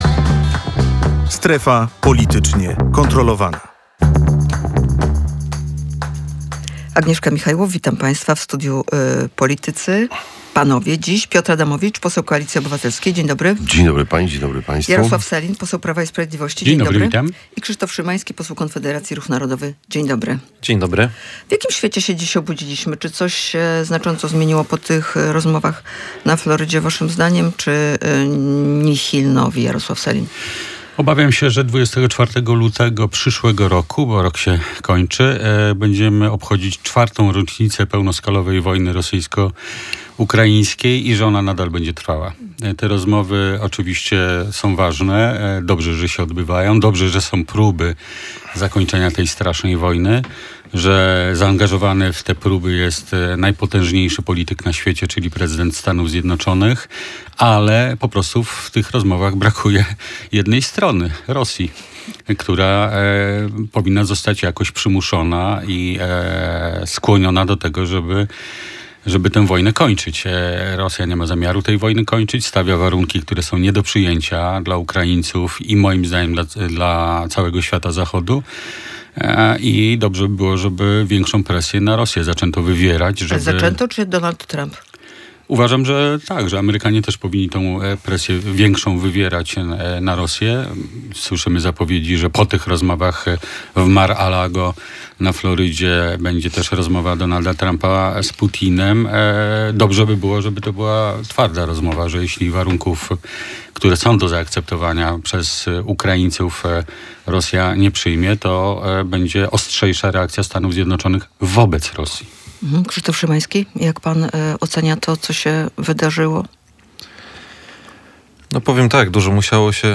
Piotr Adamowicz – poseł Koalicji Obywatelskiej, Jarosław Sellin – poseł Prawa i Sprawiedliwości i Krzysztof Szymański – poseł Konfederacji.